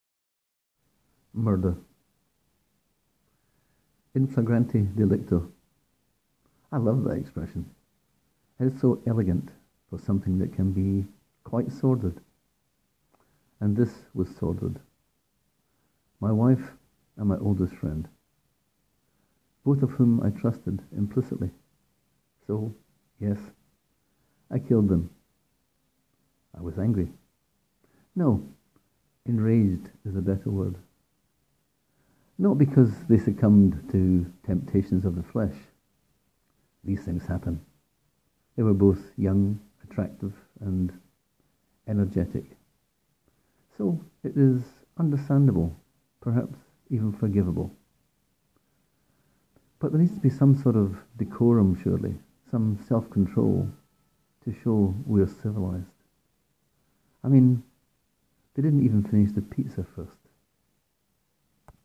Click here to hear this 1 minute story read by the author: